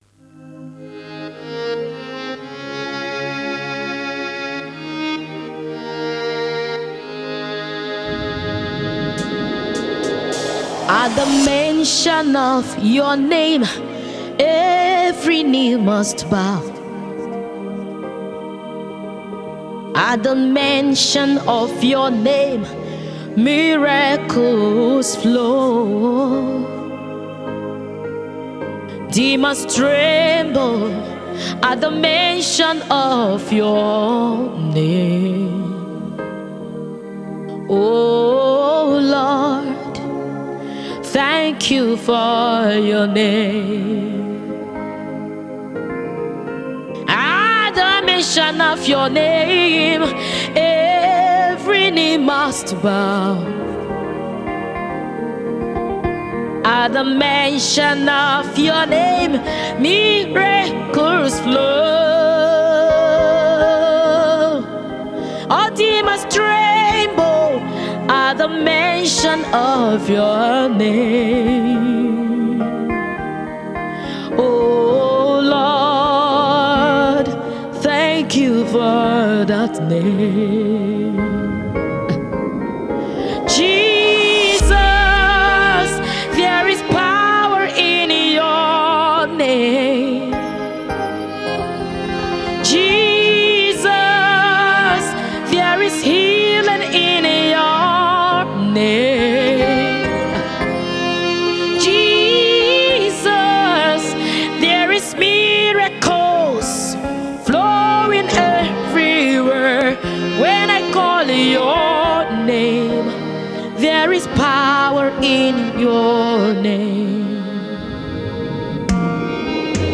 GOSPEL
SOUL LIFTING SONG
Lyrics for worship